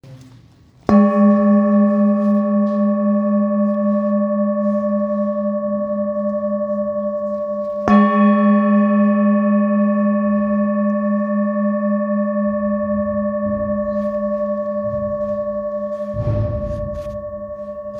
Singing Bowl, Buddhist Hand Beaten, with Fine Etching Carving, Samadhi, Select Accessories
Material Seven Bronze Metal